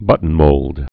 (bŭtn-mōld)